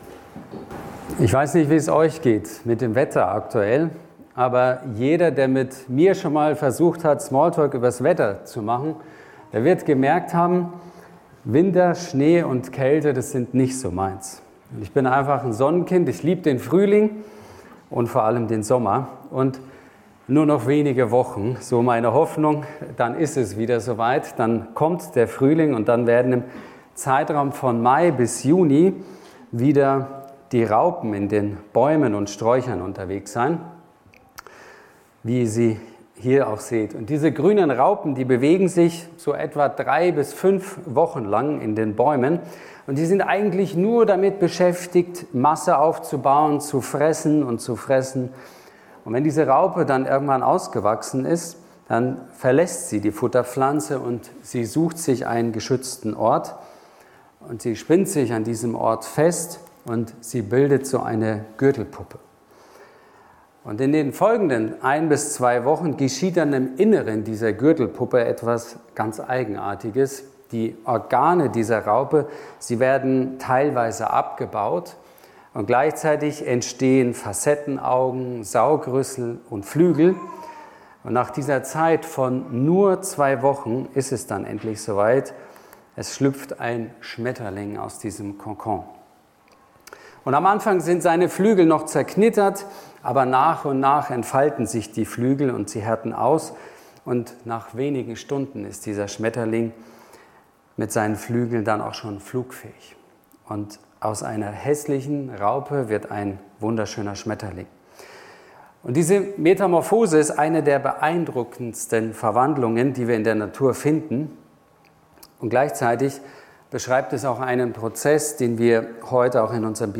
2026-02-22_Taufpredigt.mp3